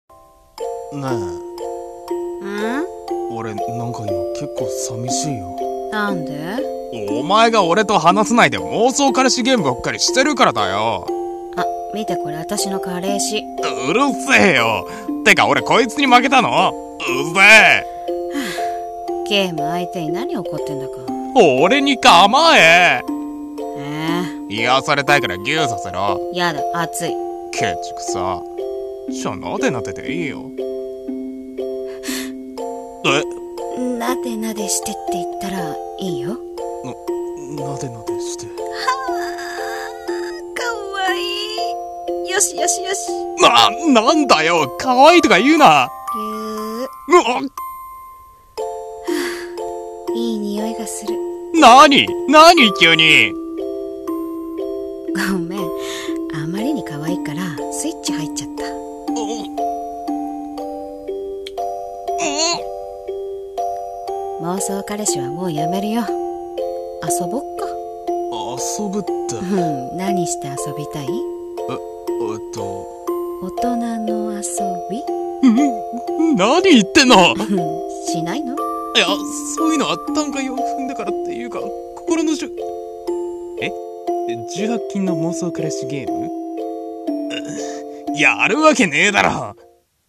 【声劇】攻め彼女